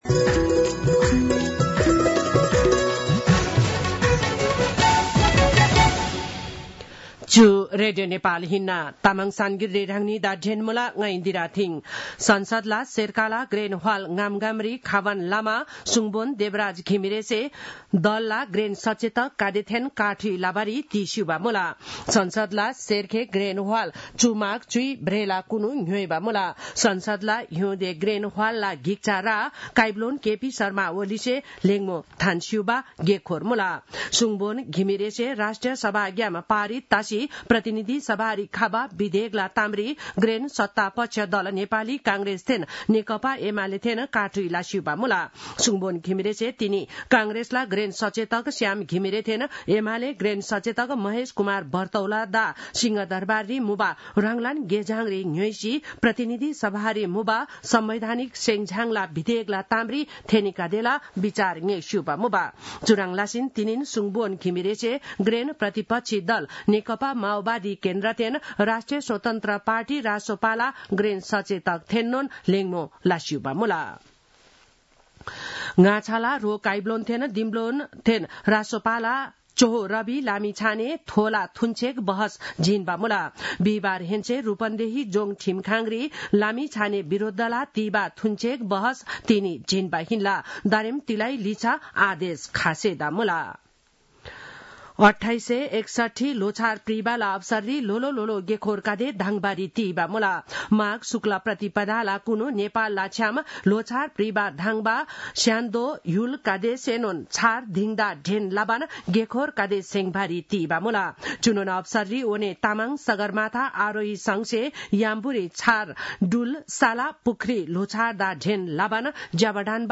तामाङ भाषाको समाचार : १४ माघ , २०८१